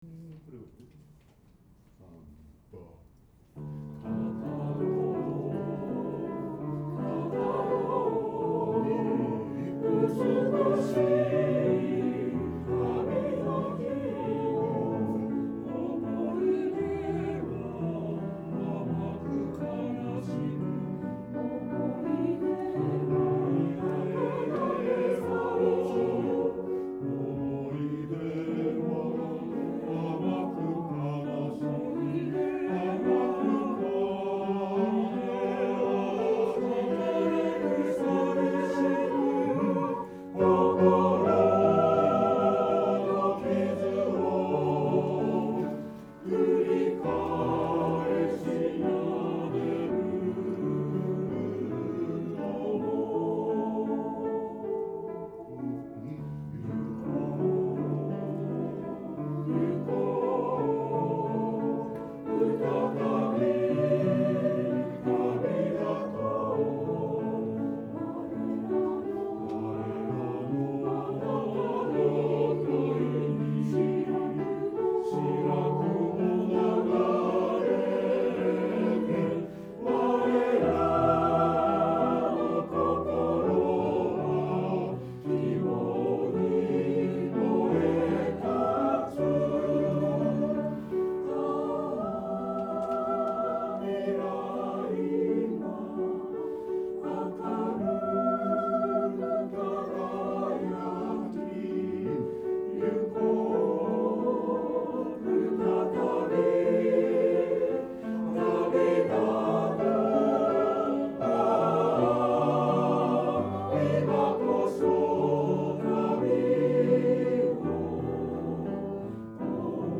練習場所：アスピア明石北館　8階学習室801A・B（明石市）
▶録音（Alt・Bass）